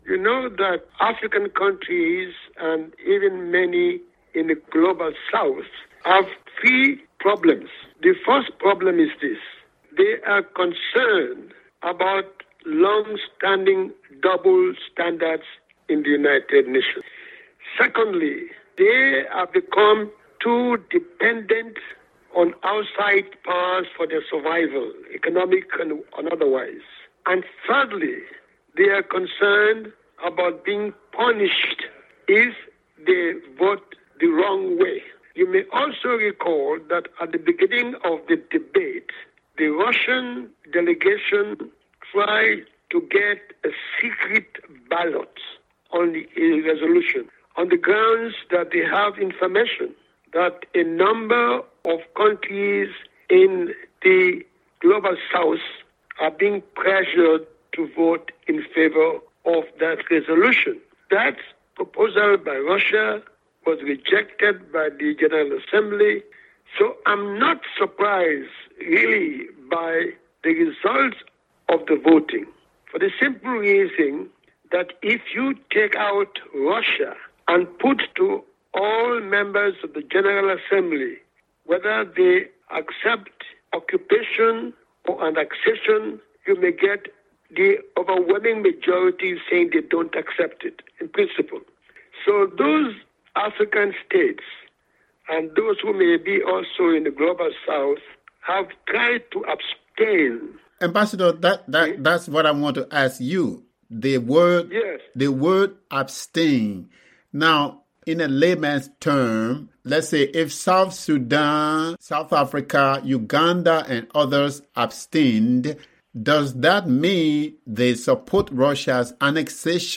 The interview was edited for brevity and clarity.